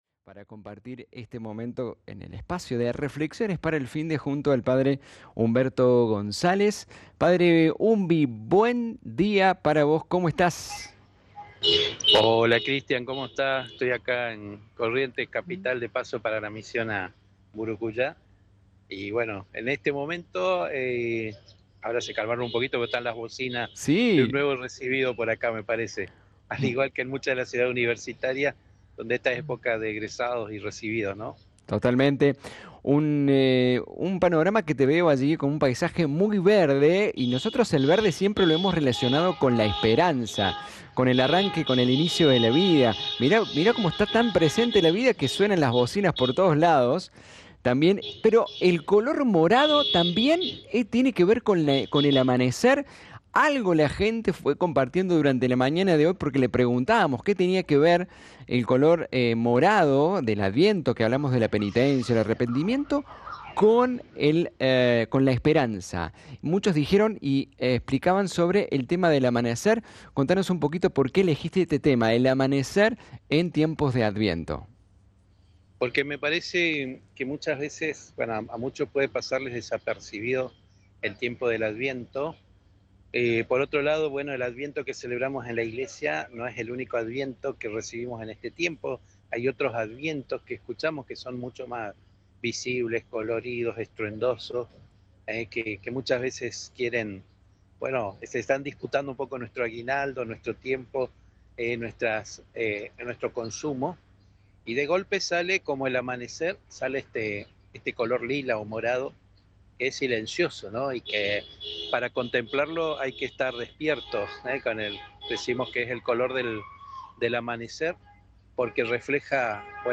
Una reflexión profunda